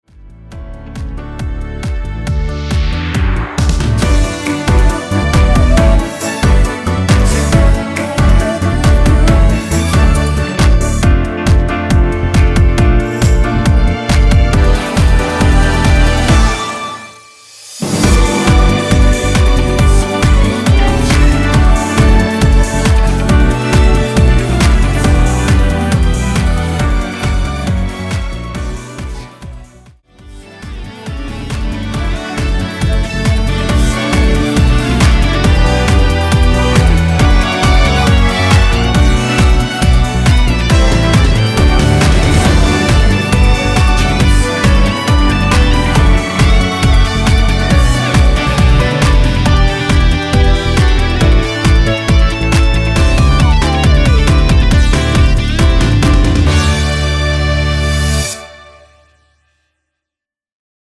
원키에서 (-2)내린 코러스 포함된 MR 입니다.
엔딩이 페이드 아웃이라?노래 하시기 좋게 엔딩을 만들어 놓았습니다.(미리듣기 참조)
앞부분30초, 뒷부분30초씩 편집해서 올려 드리고 있습니다.
중간에 음이 끈어지고 다시 나오는 이유는